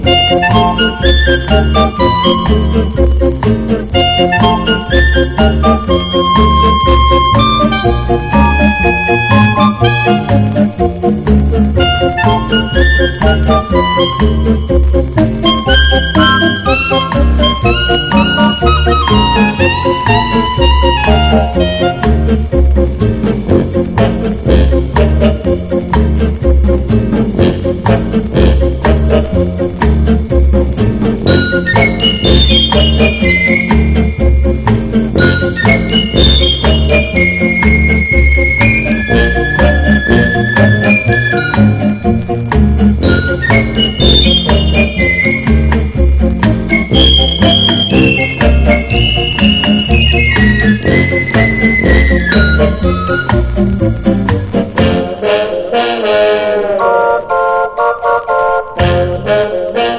BREAKBEATS/HOUSE / DISCO HOUSE / GARAGE HOUSE (UK)